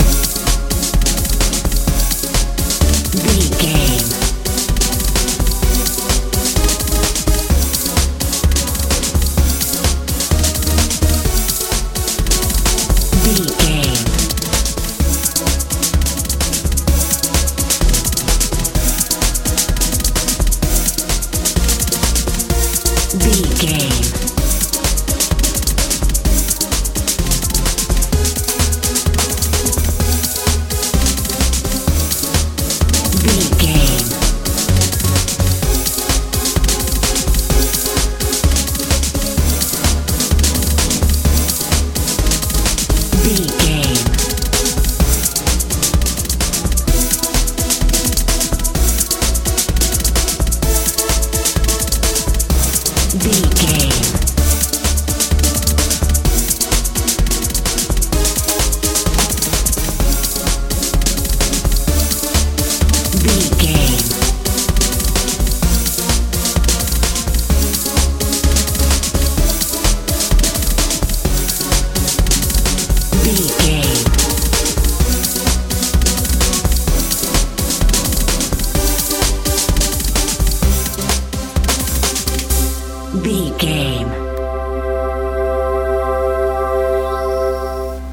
modern pop
Ionian/Major
C♯
futuristic
powerful
synthesiser
bass guitar
drums
poignant
playful
mechanical
industrial
driving
bouncy
drum machine